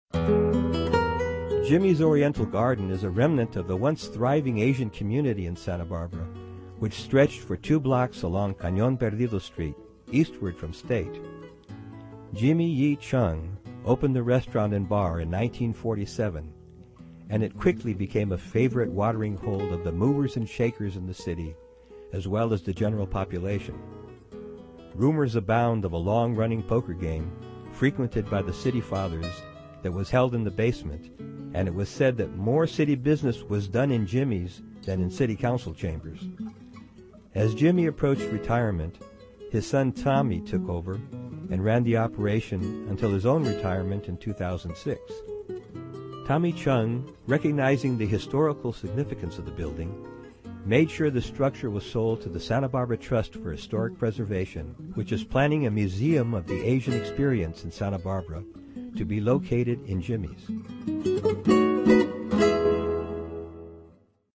This audio tour will take you to some of the most architecturally significant structures in downtown Santa Barbara and will introduce you to the diverse influences that have shaped the look of our town.